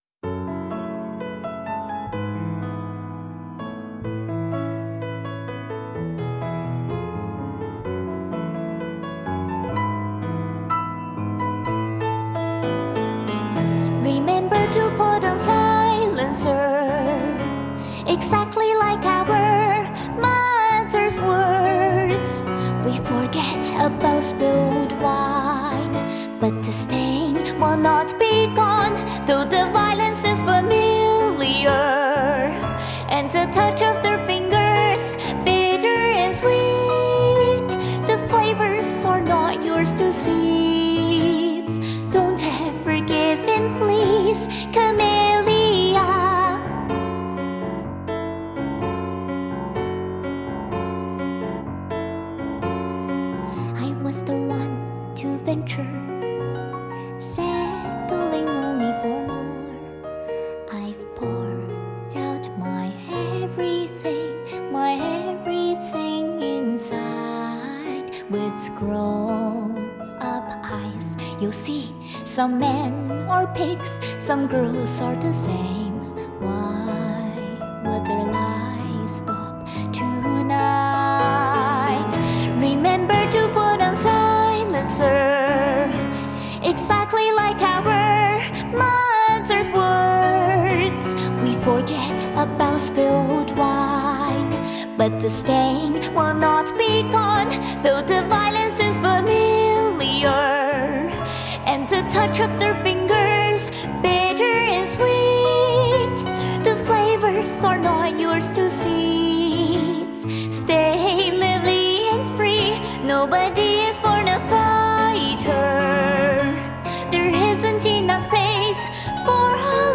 Vocal+Piano Unplugged